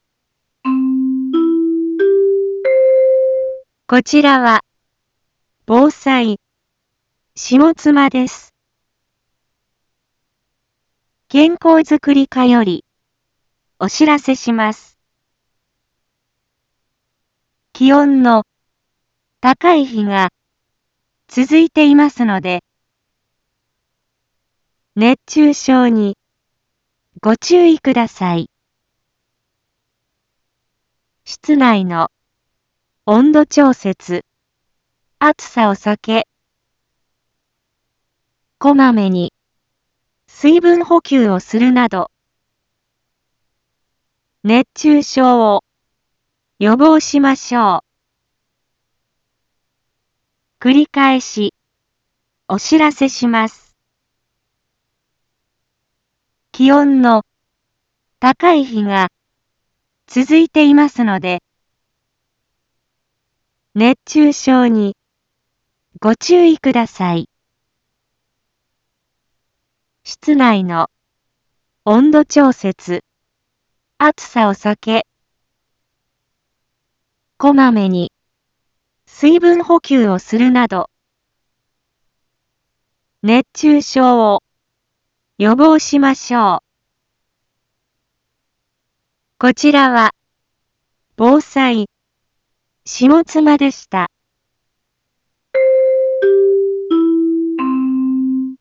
一般放送情報
Back Home 一般放送情報 音声放送 再生 一般放送情報 登録日時：2023-08-14 11:01:42 タイトル：熱中症注意のお知らせ インフォメーション：こちらは、防災、下妻です。